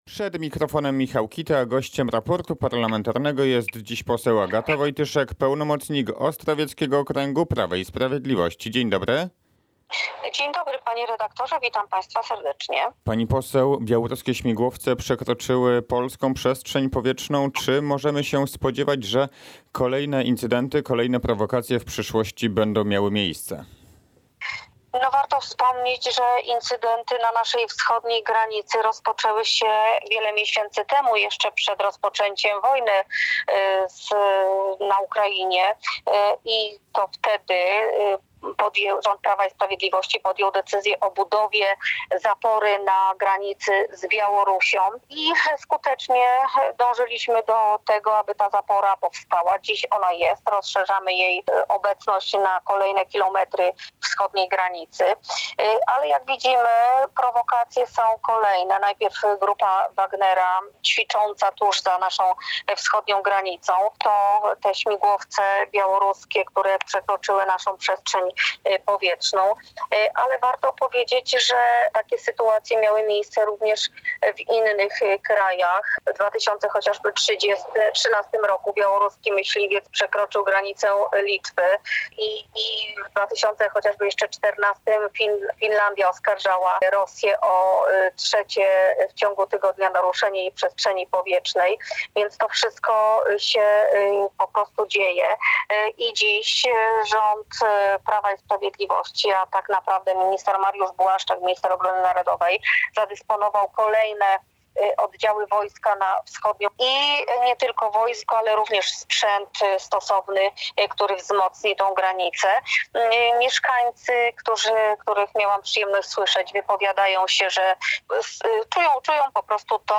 – Incydenty na naszej wschodniej granicy rozpoczęły się jeszcze przed rozpoczęciem wojny na Ukrainie – stwierdziła poseł Agata Wojtyszek z Prawa i Sprawiedliwości, w Raporcie Parlamentarnym Radia Kielce.